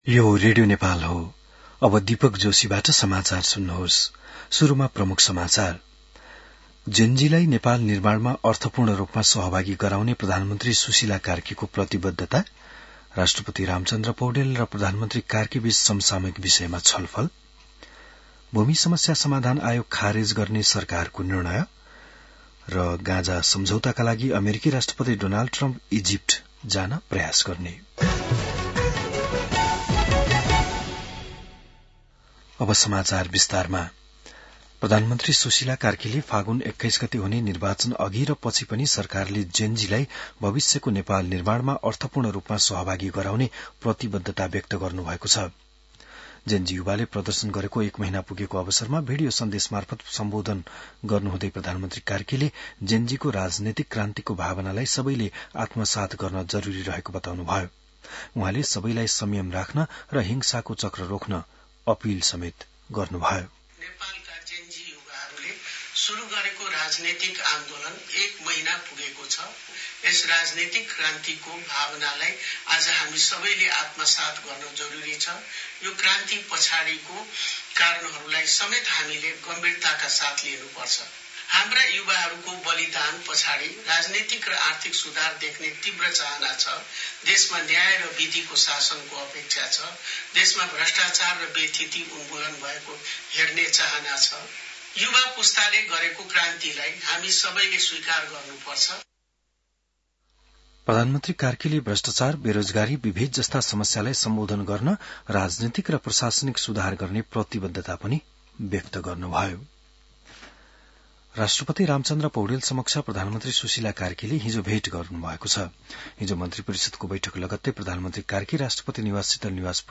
बिहान ९ बजेको नेपाली समाचार : २४ असोज , २०८२